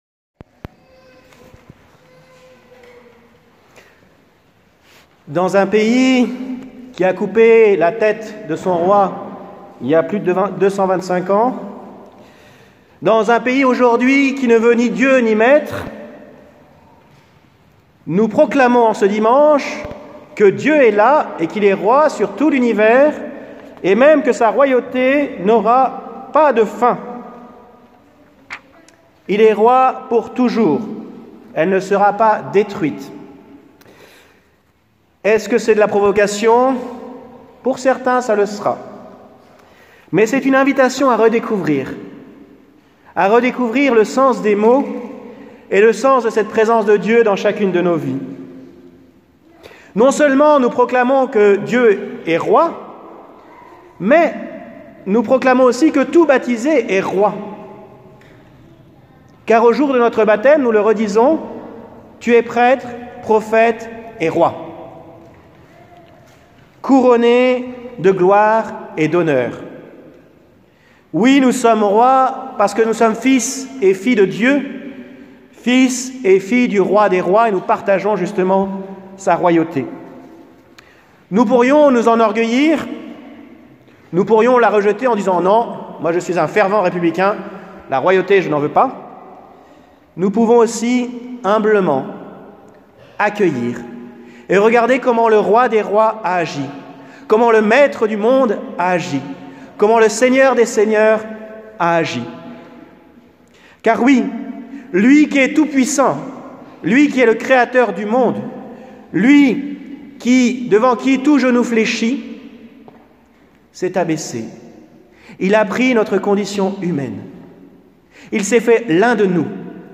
Homélie du vendredi 6 février 2026